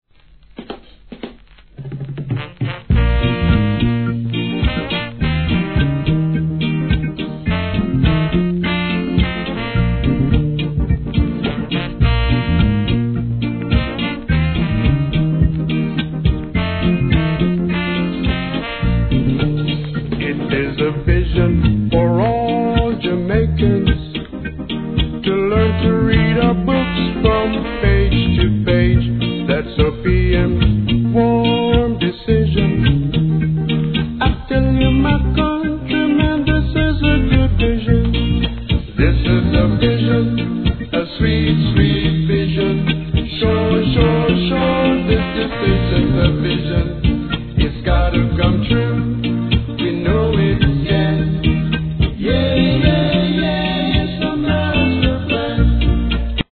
REGGAE
1972年SWEETな好ROCKSTEADY!!